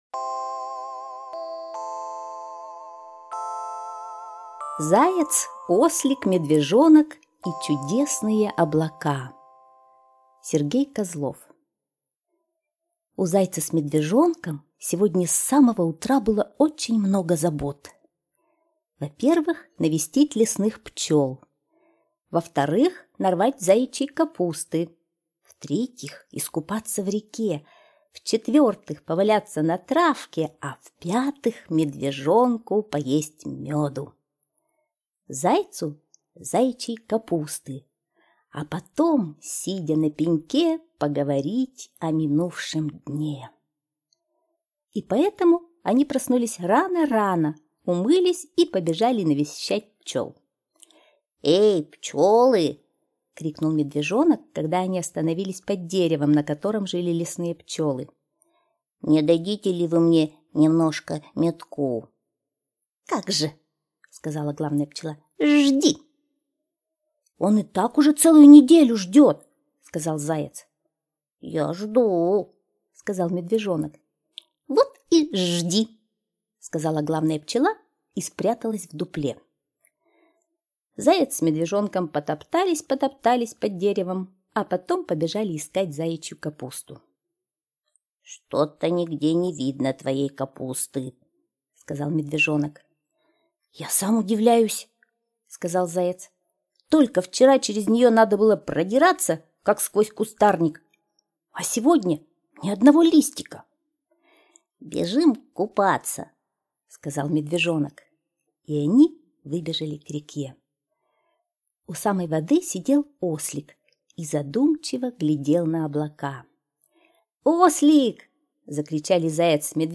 Слушайте Заяц, Ослик, Медвежонок и чудесные облака - аудиосказка Козлова С.Г. Сказка про то, как Заяц и Медвежонок и Ослик рассматривали облака.